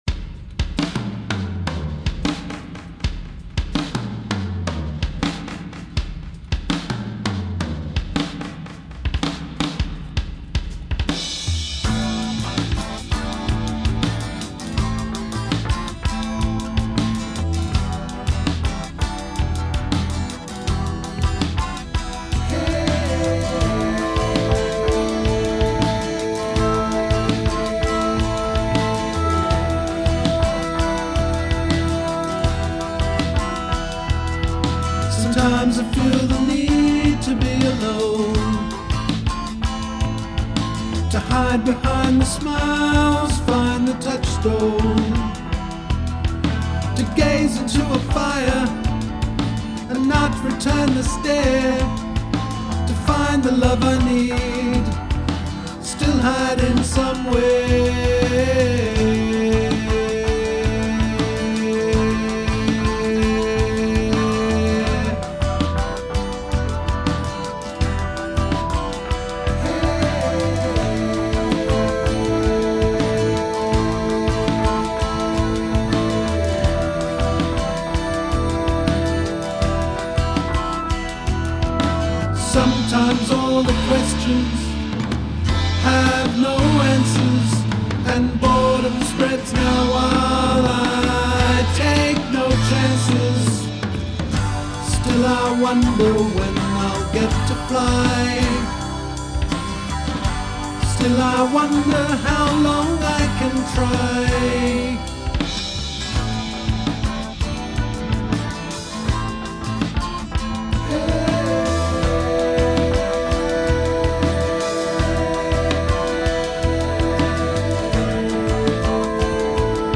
Vocals, Acoustic Guitar & Drums.
Vocals & Bass.
Keyboards.